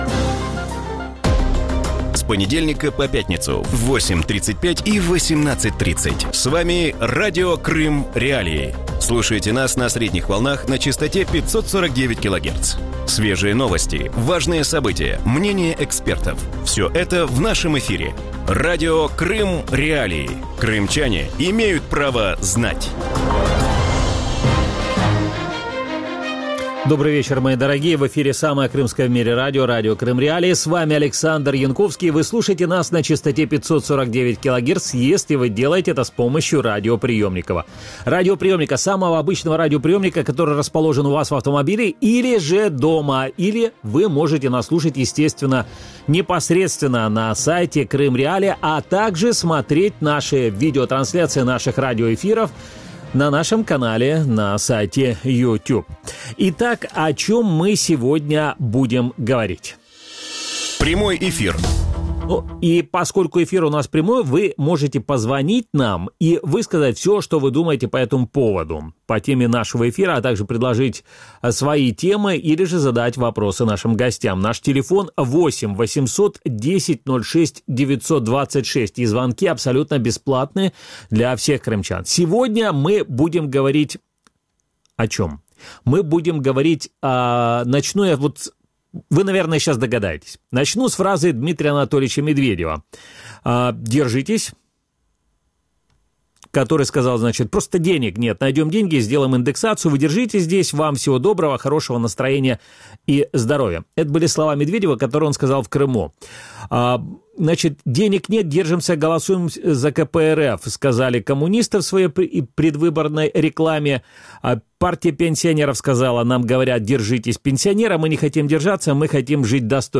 У вечірньому ефірі Радіо Крим.Реалії обговорюють ситуацію з пенсійним забезпеченням у Росії і в Криму. Чи варто кримчанам очікувати індексації пенсій, навіщо держава вирішила виплатити пенсіонерам по 5000 рублів і які бюджетні витрати російський уряд уріже в наступному році?